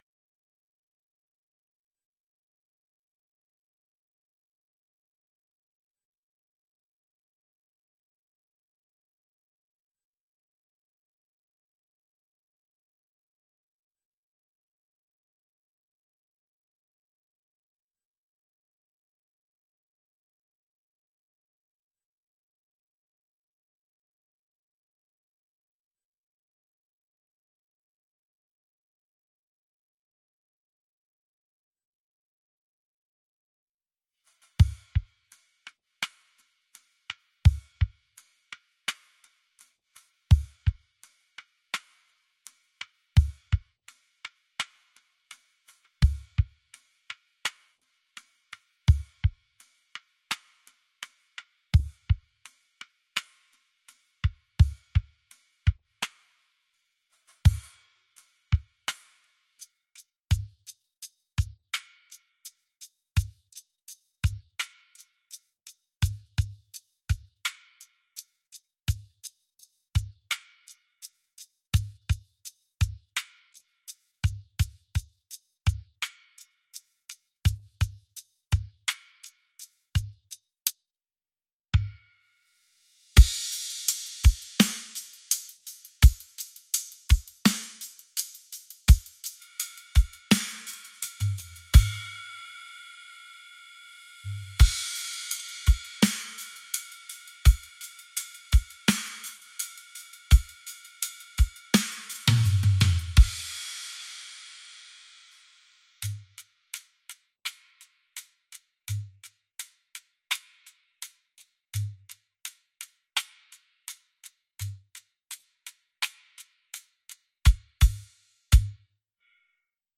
Instruments